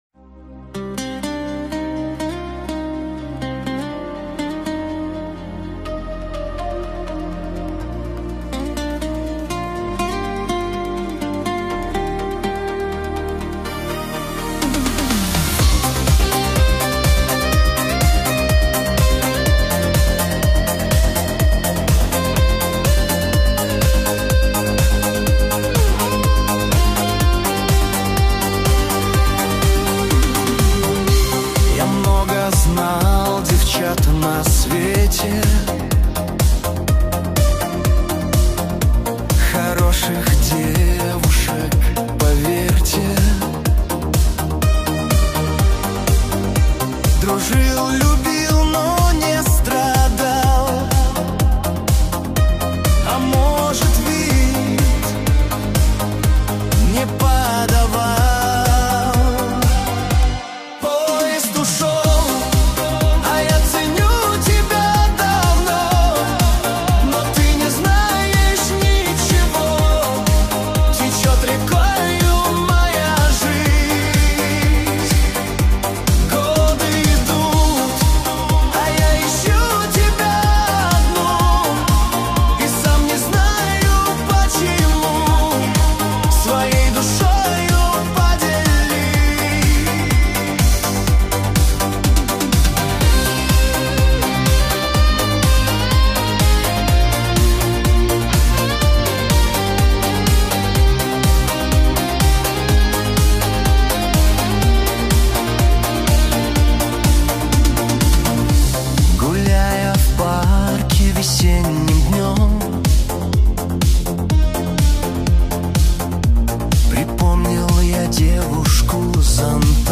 Качество: 320 kbps, stereo
Поп музыка, Песня про поезд